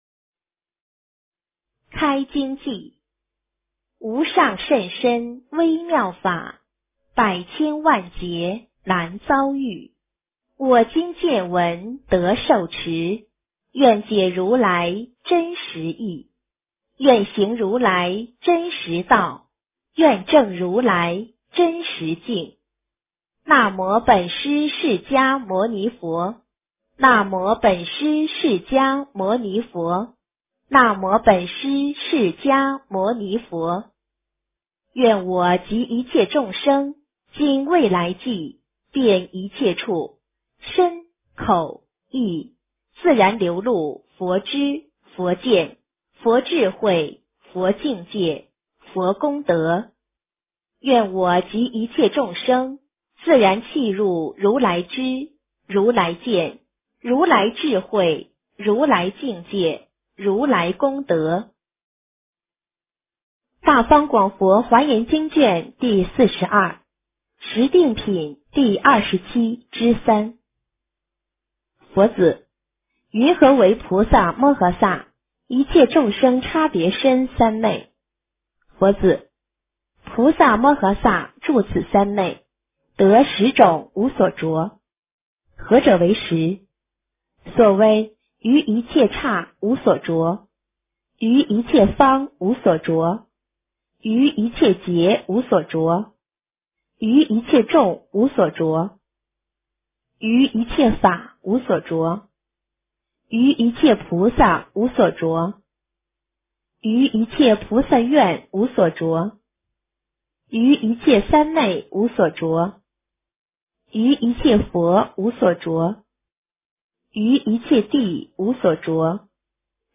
华严经42 - 诵经 - 云佛论坛